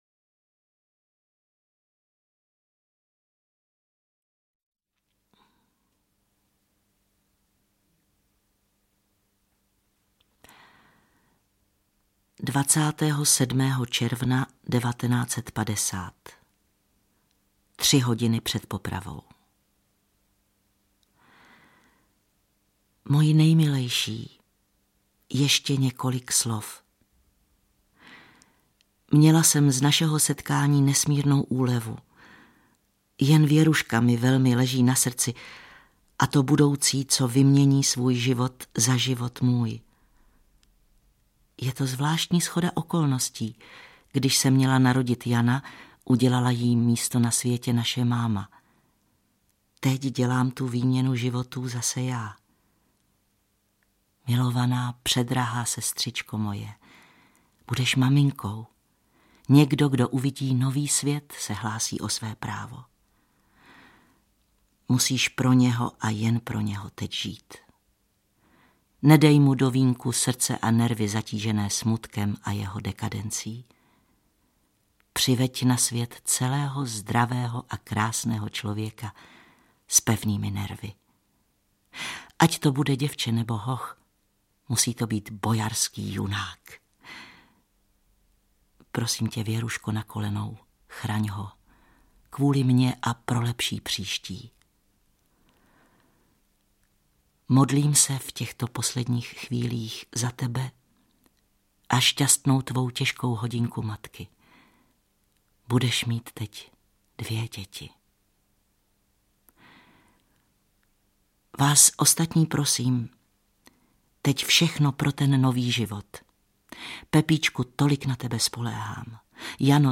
Čte Hana Kofránková.